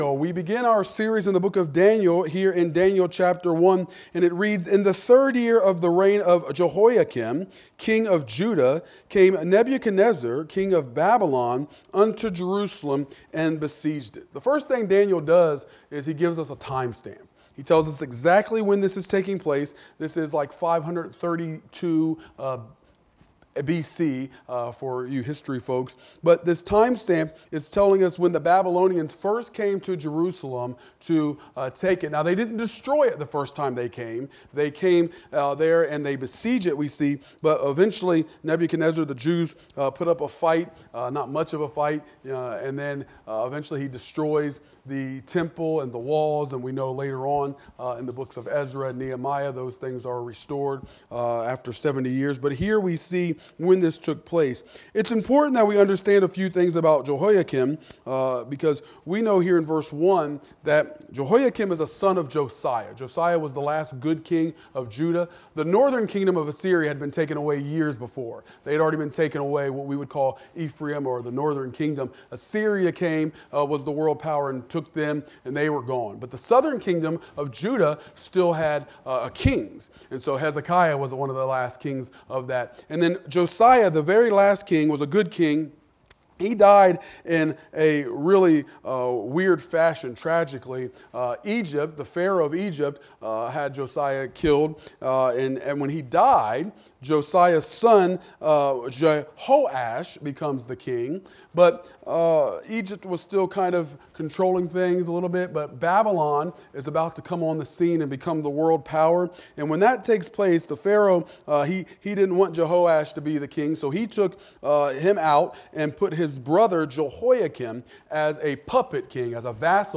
Wednesday Midweek Service